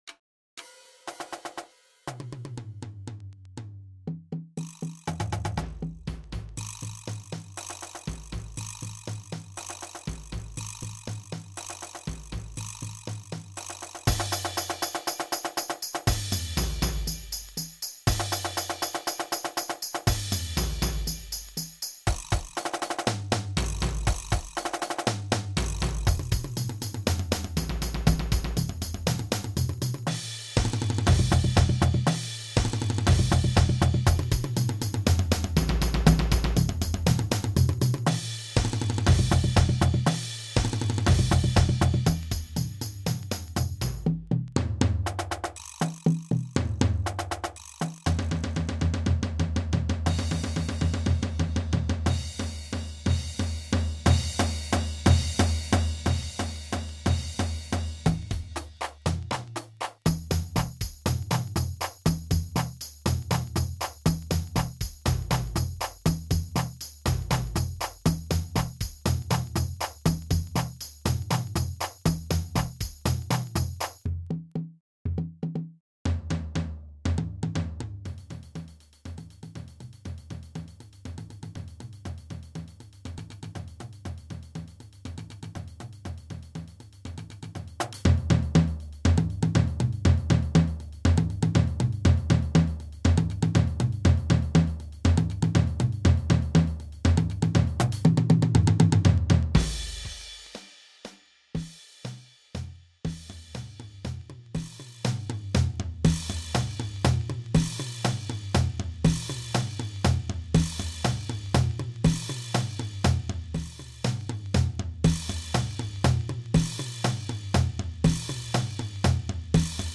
Multi-Percussion
Snare drum Percussion Tom toms Drumset Bass drums